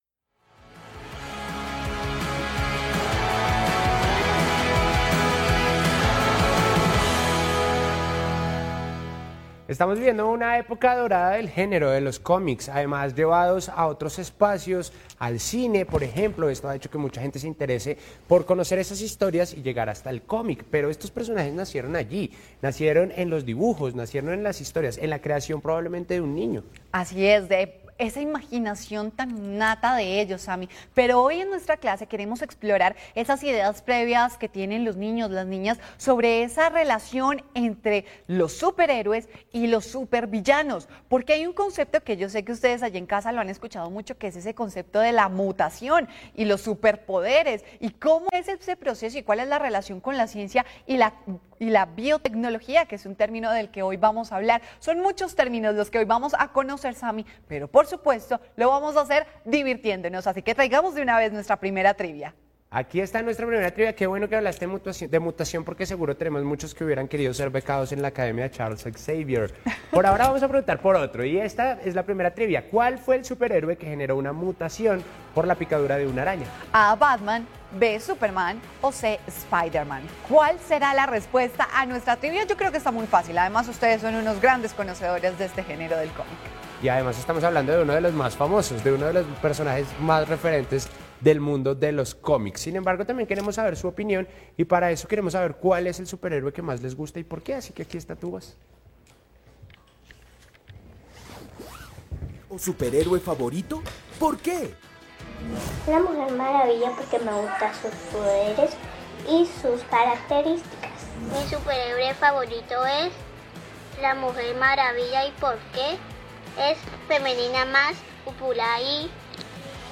En esta emisión, dirigida a estudiantes de básica secundaria y emitida por Señal Colombia, Canal Institucional y Radio Nacional de Colombia, el profesor invitado al programa respondió algunas de las siguientes preguntas: ¿Cómo los personajes de los cómics se relacionan con la ciencia y la tecnología?